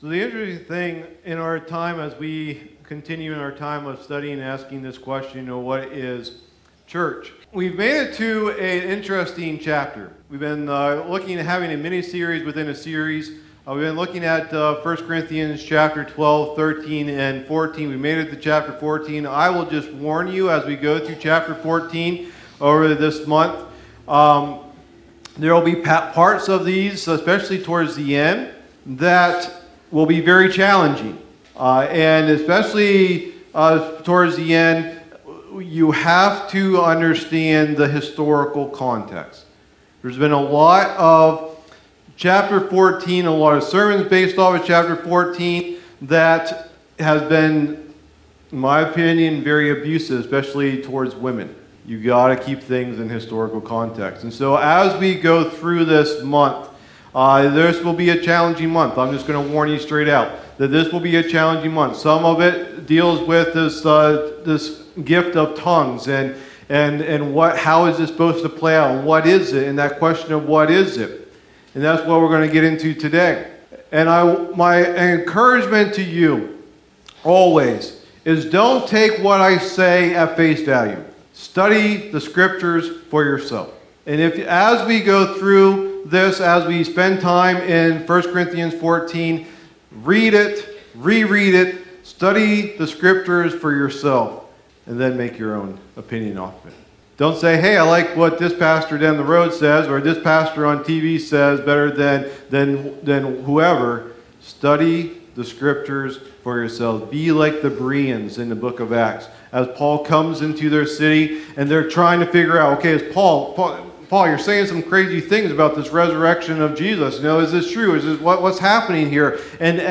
Message #11 of the "What is Church?" teaching series